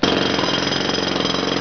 Jackhammer
Jackhammer.wav